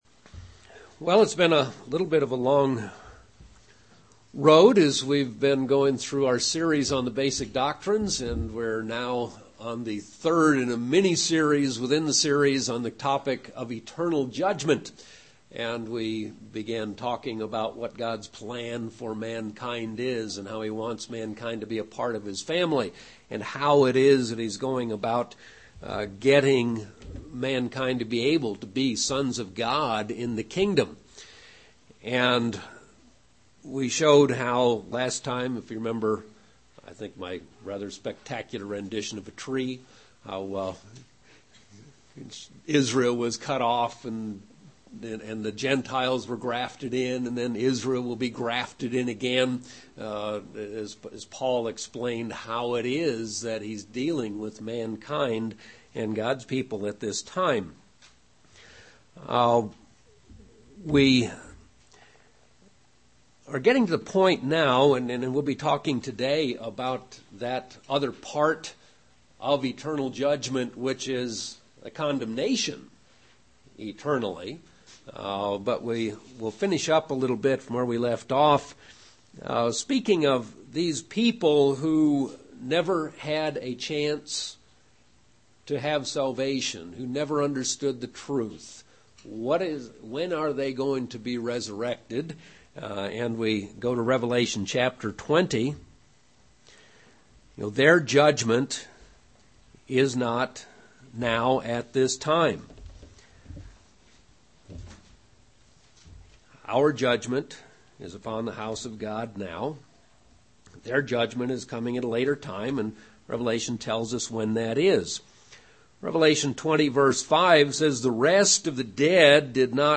Part 3 of a sermon series on eternal judgement.